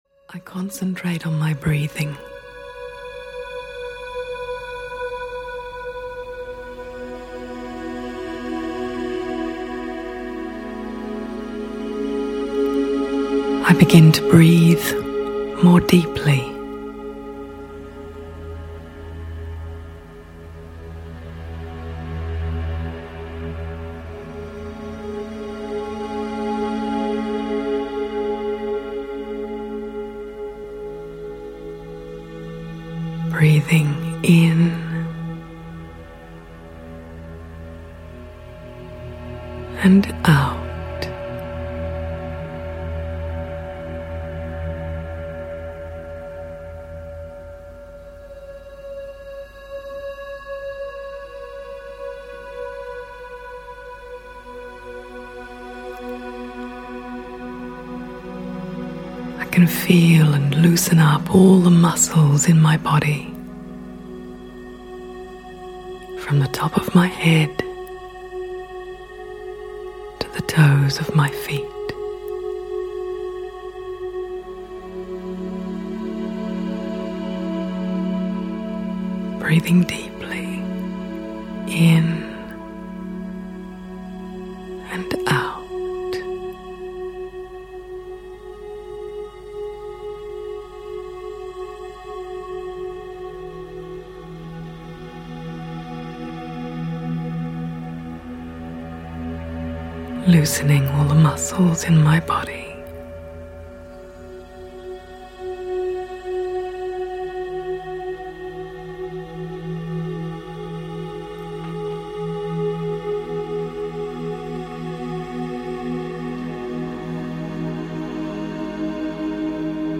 Ukázka z knihy
"Moving On – Part One" by Brahma Khumaris offers its listeners a guided, musical meditation to help them unwind, relax, and move on.